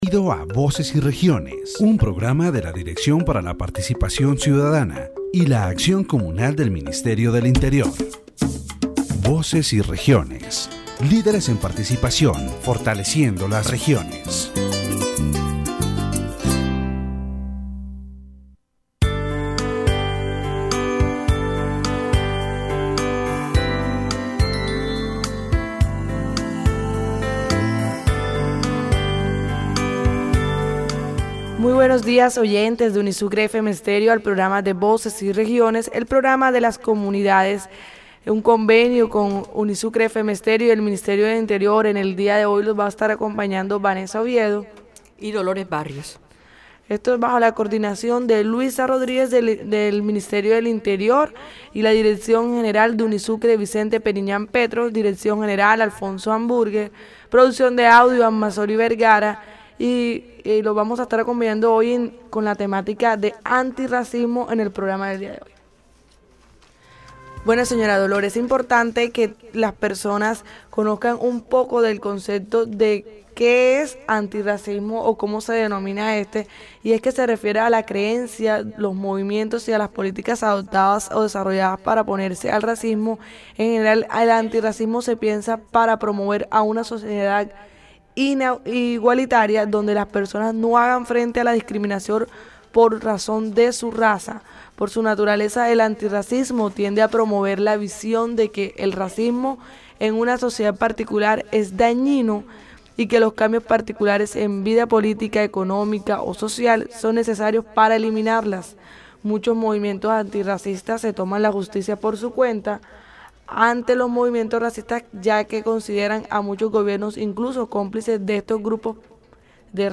The radio program "Voces y Regiones" delves into the issue of anti-racism in Colombia. It highlights the pervasive nature of discrimination, particularly in educational settings and the media. The speakers emphasize the importance of education in fostering a more equitable society and discuss the role of laws and policies in combating racism.